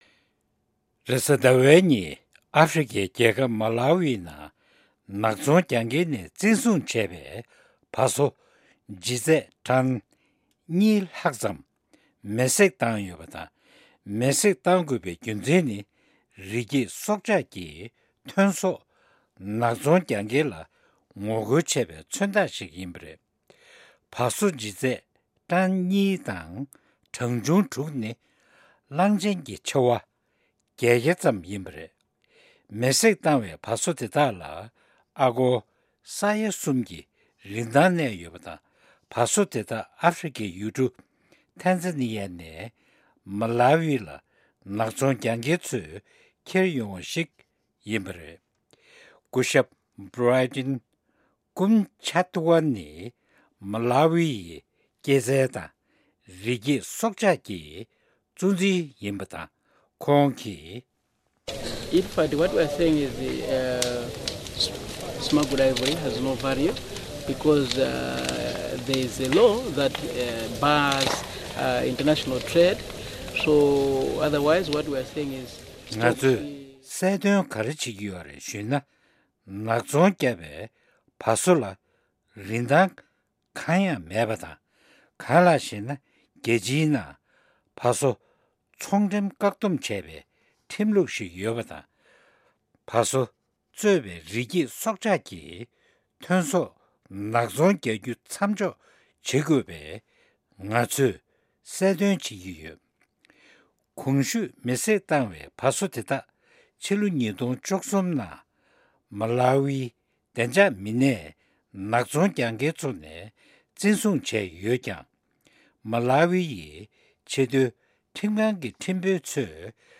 ཕབ་བསྒྱུར་དང་སྙན་སྒྲོན་ཞུས་གནང་གི་རེད།